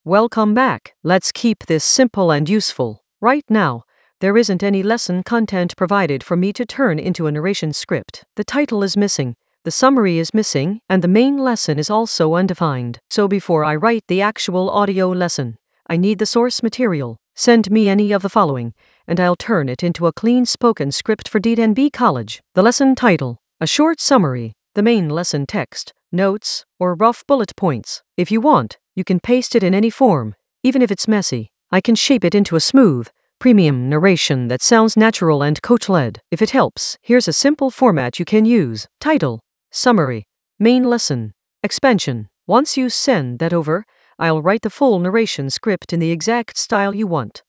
An AI-generated beginner Ableton lesson focused on Marcus Intalex beats that slap in the Drums area of drum and bass production.
Narrated lesson audio
The voice track includes the tutorial plus extra teacher commentary.